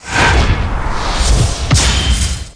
SFX气流打斗魔法攻击技能音效下载
这是一个免费素材，欢迎下载；音效素材为气流打斗魔法攻击技能音效， 格式为 wav，大小1 MB，源文件无水印干扰，欢迎使用国外素材网。